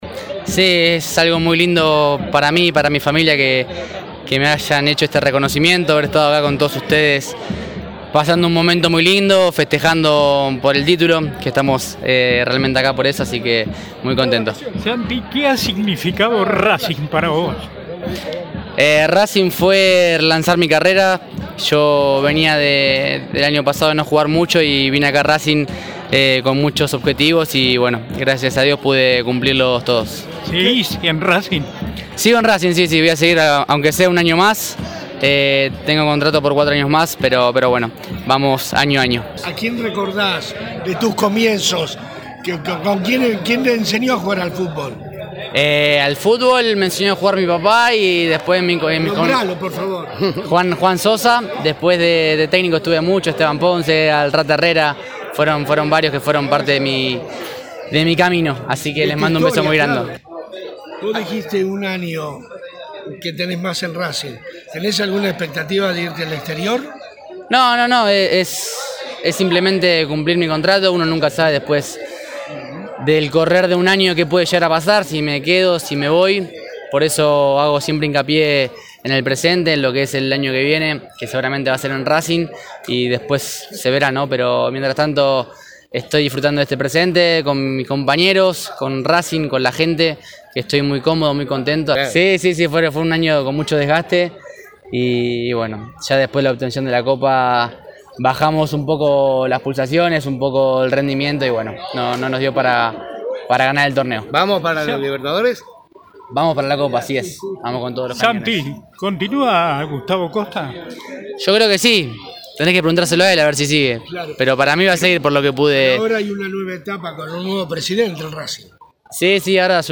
Fue en La Trocha y se contó con una gran cantidad de fanáticos.